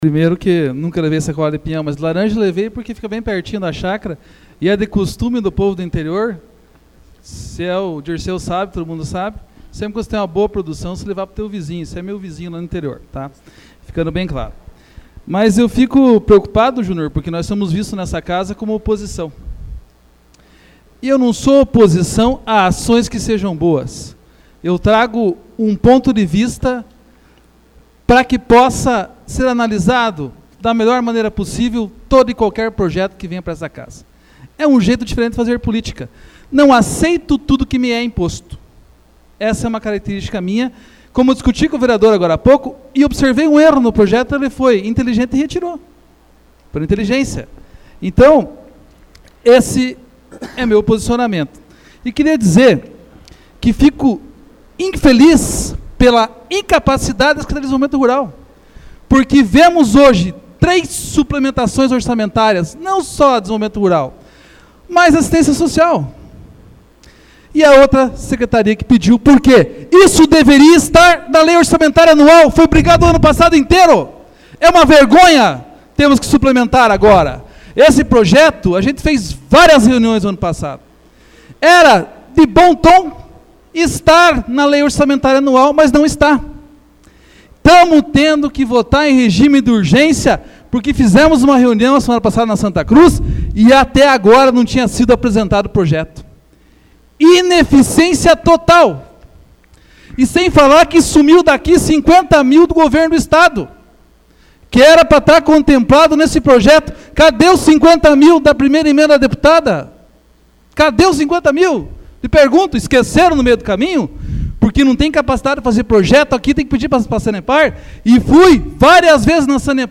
Discussão AVULSO 18/03/2014 João Marcos Cuba